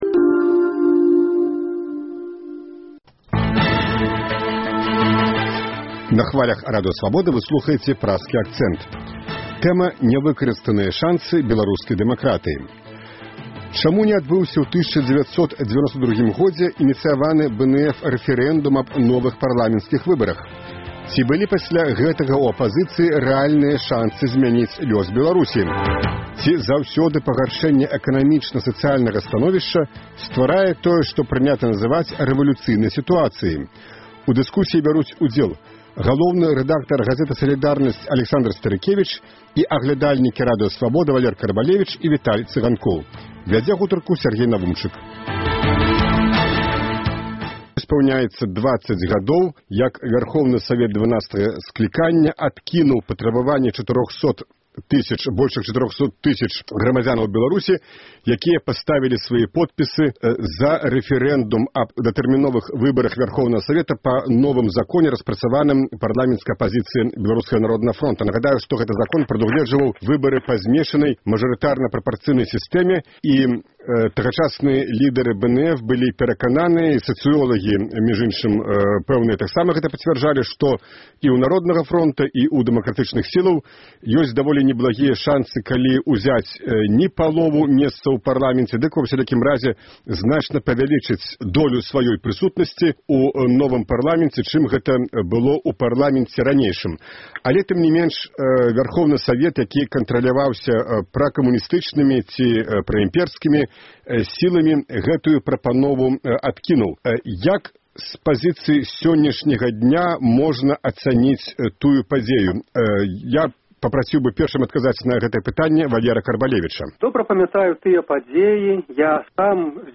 У дыскусіі бяруць удзел